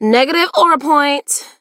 BS_juju_hurt_vo_02.mp3